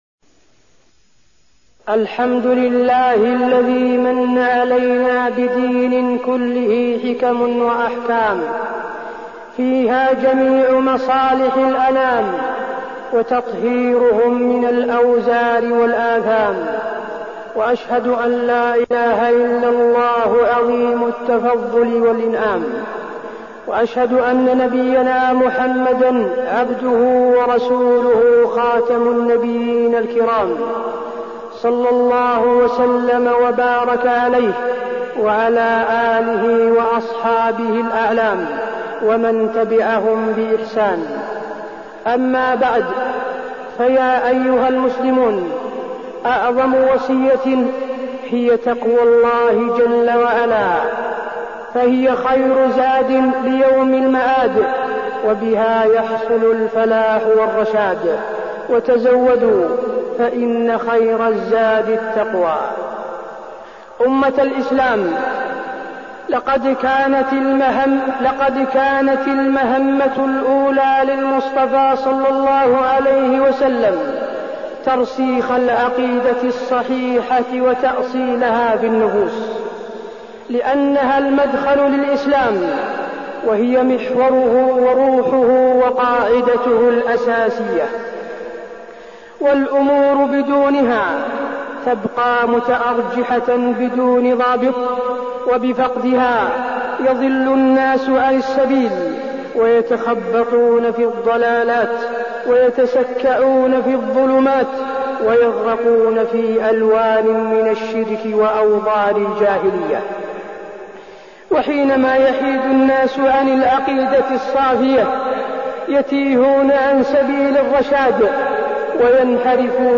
خطبة مظاهر التوحيد في الحج وفيه: قضية المسلم الكبرى، الحج هو دعوة التوحيد، وقفات من تحقيق التوحيد في الحج
تاريخ النشر ٢ ذو الحجة ١٤١٩ المكان: المسجد النبوي الشيخ: فضيلة الشيخ د. حسين بن عبدالعزيز آل الشيخ فضيلة الشيخ د. حسين بن عبدالعزيز آل الشيخ مظاهر التوحيد في الحج